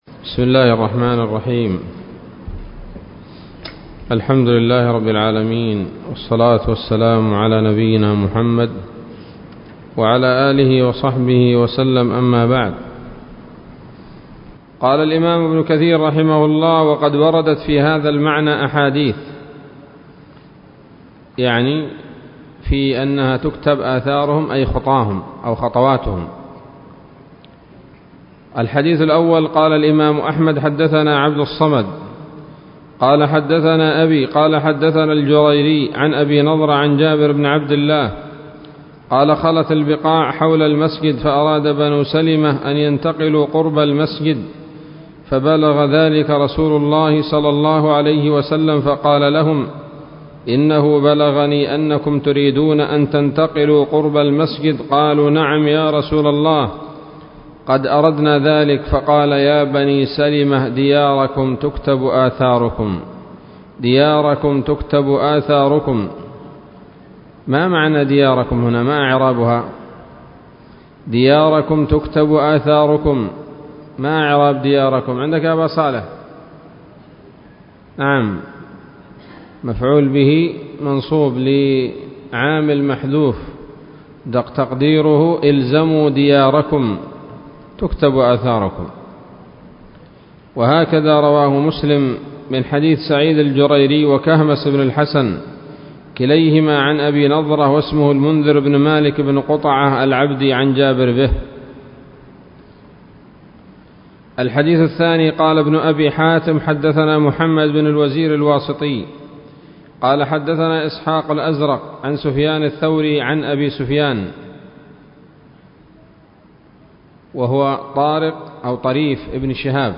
الدرس الثالث من سورة يس من تفسير ابن كثير رحمه الله تعالى